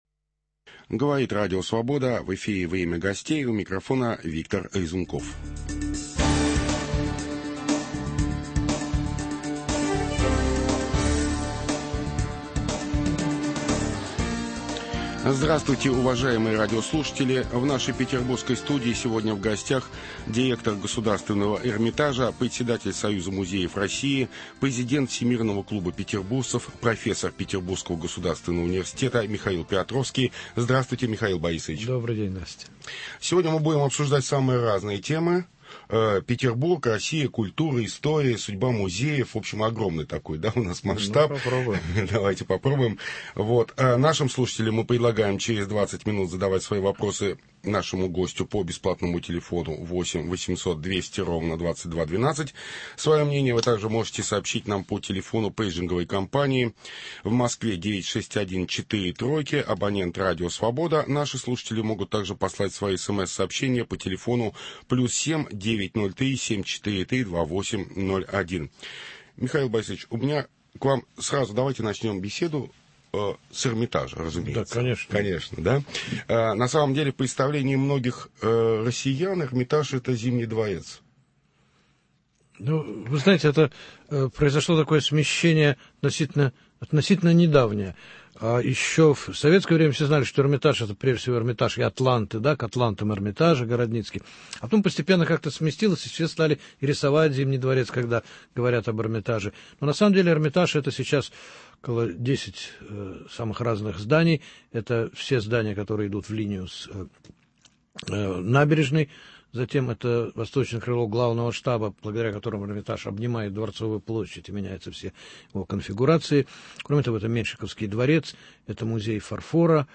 О России, о Петербурге, о культуре, о сложных взаимоотношениях между арабскими и европейскими странами беседуем с директором Государственного Эрмитажа, профессором Михаилом Пиотровским.